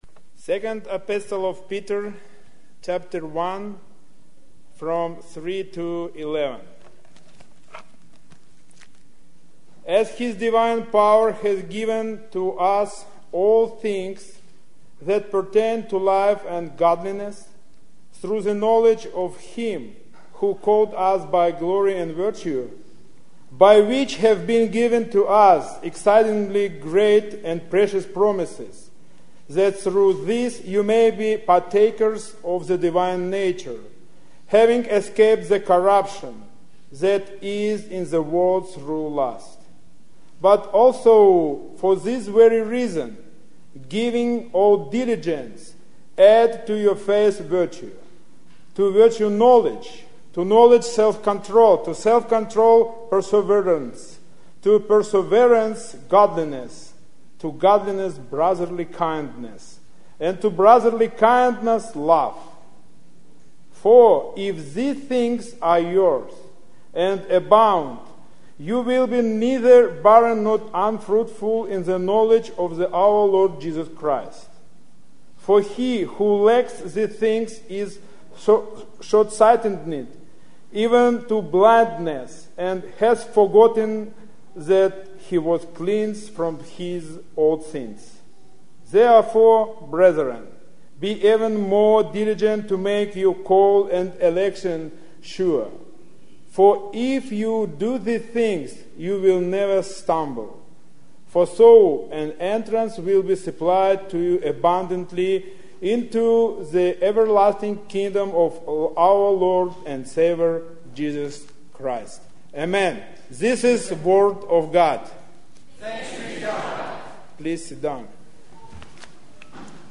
Sermons by visiting preachers
Service Type: Sunday worship